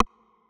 MetroRimShot.wav